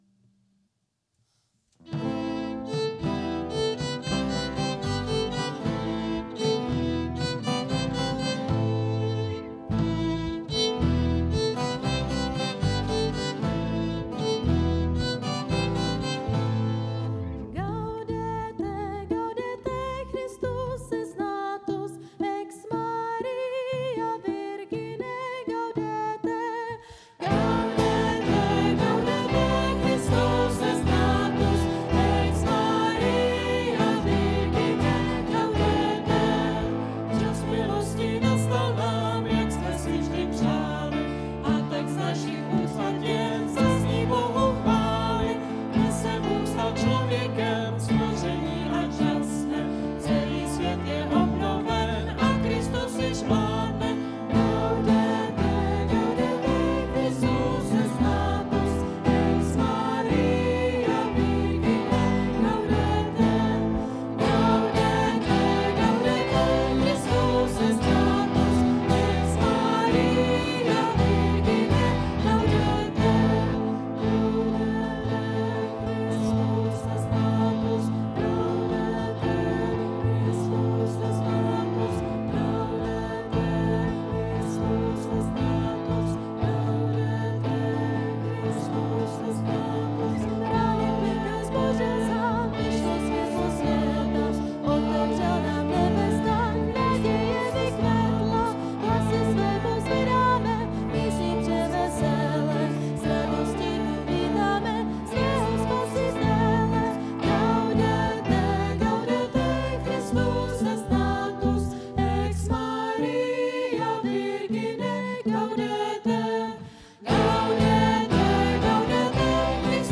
Půlnoční bohoslužba